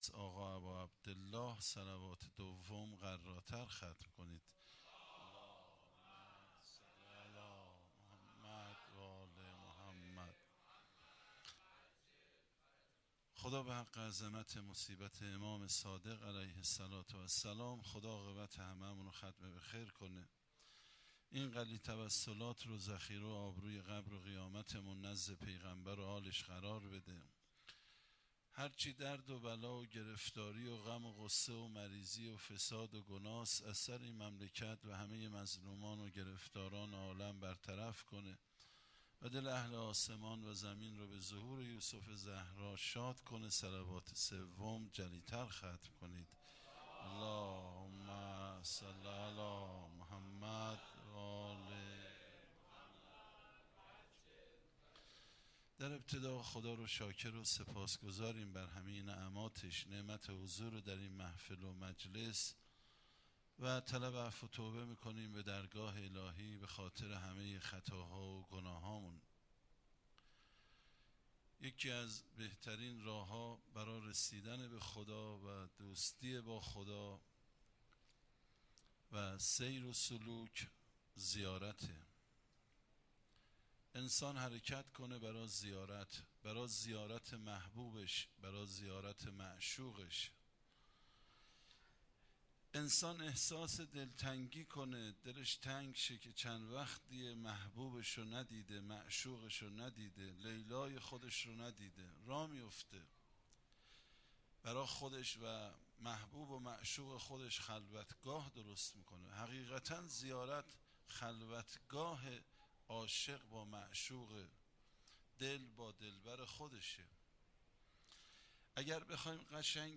شهادت امام صادق علیه السلام 96 - هیئت مکتب المهدی عجل الله تعالی فرجه الشریف
سخنرانی